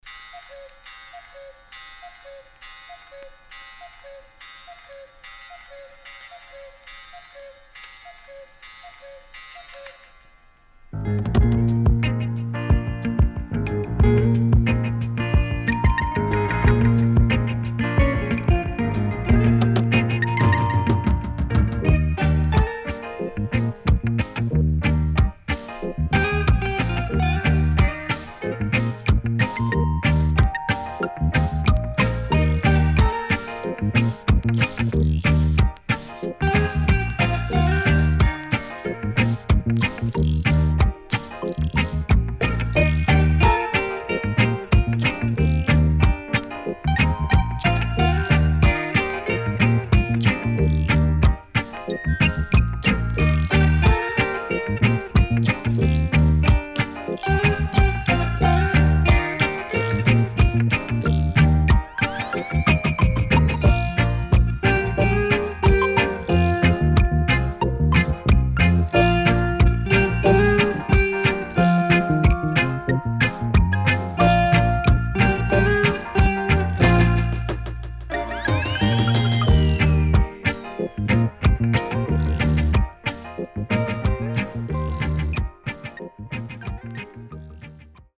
HOME > Back Order [VINTAGE DISCO45]  >  INST 70's
SIDE B:少しチリノイズ入りますが良好です。